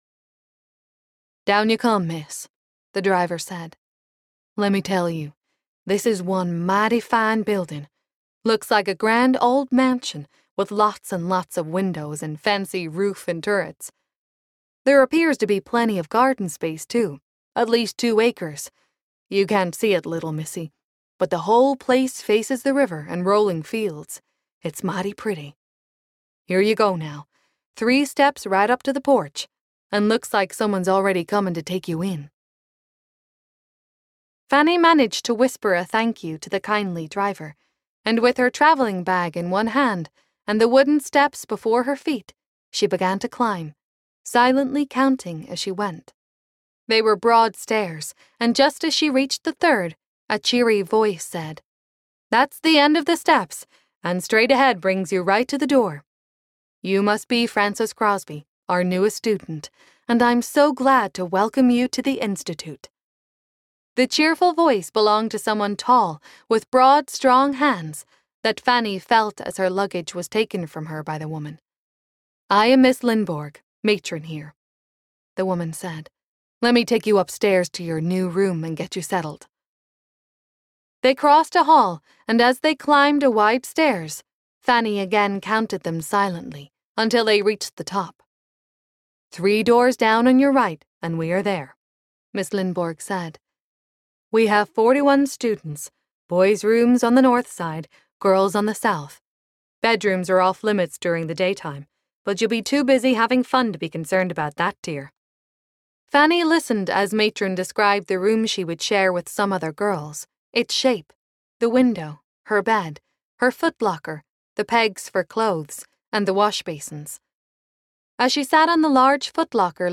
Fanny Crosby (Trailblazers Series) Audiobook
4.13 Hrs. – Unabridged